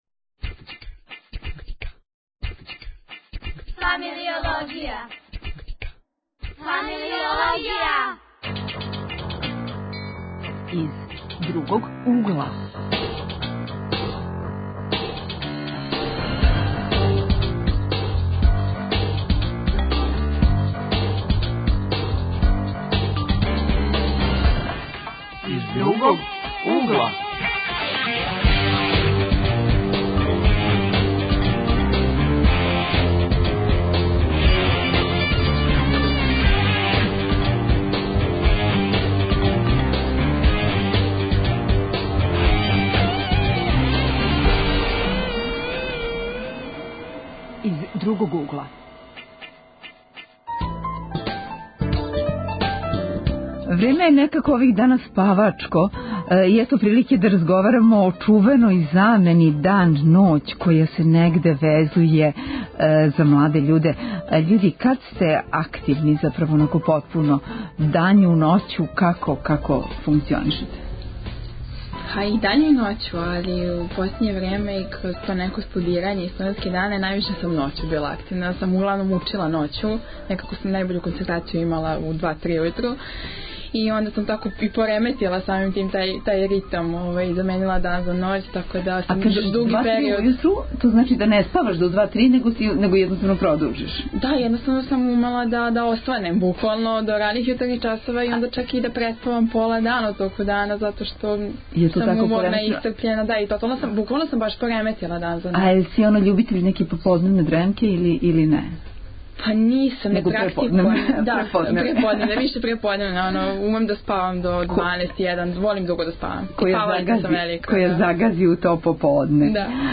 Тема: замена ноћ-дан. Гости: средњошколци и студенти.
Редовне рубрике: Глас савести - студент психологије о ноћним и дневним типовима људи.